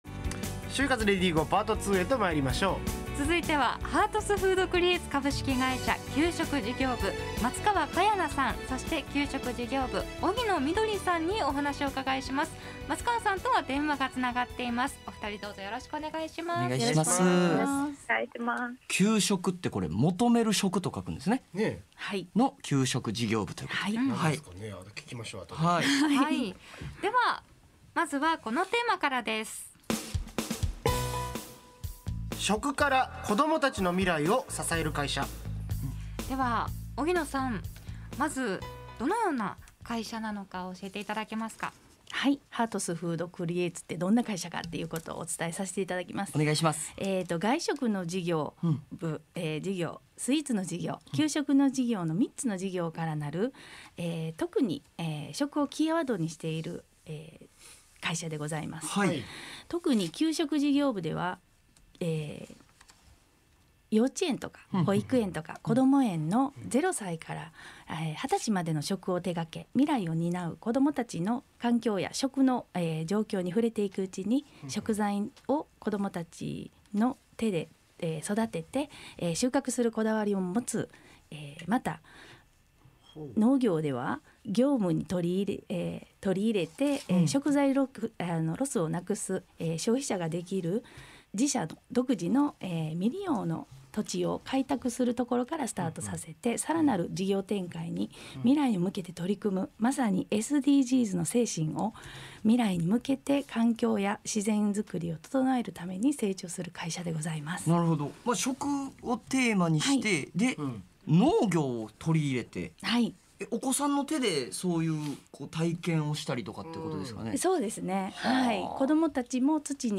【放送音声】『ネイビーズアフロのレディGO！HYOGO』2022年3月18日放送回より